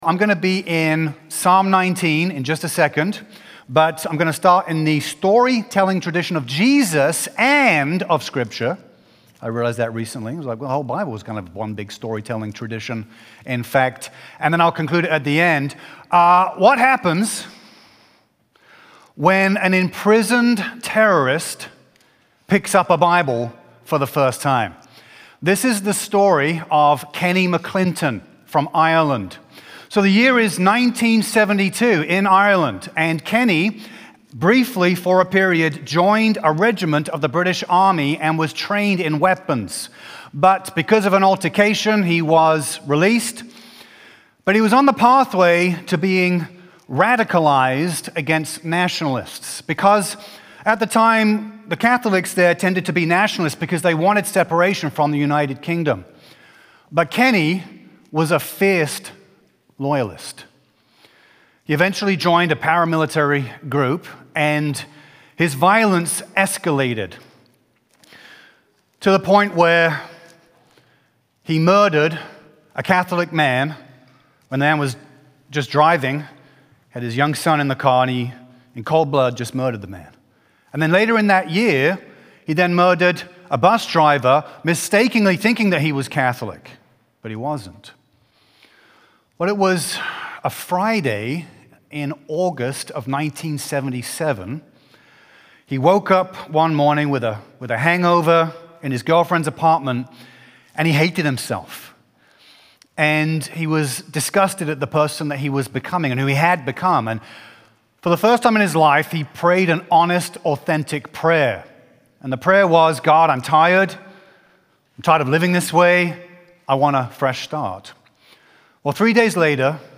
SEPT-7-2025-Full-sermon.mp3